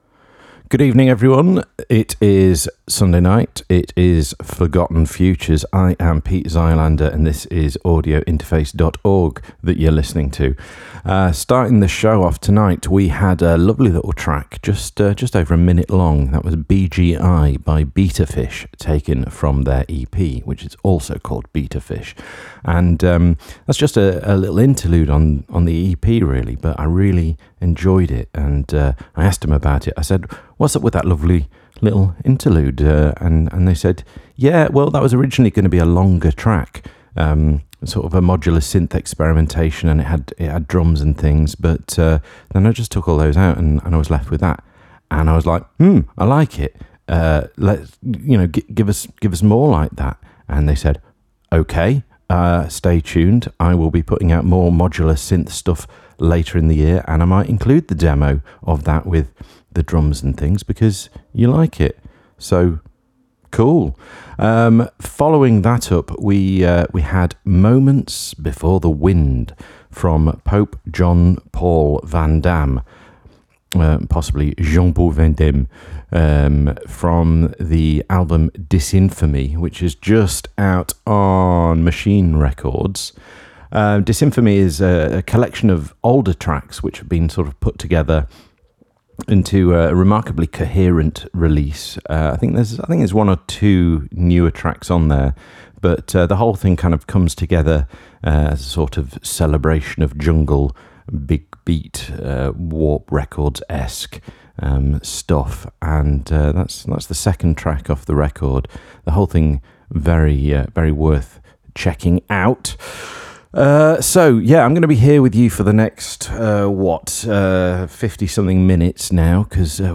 forgotten-futures-006-spoken-sections.mp3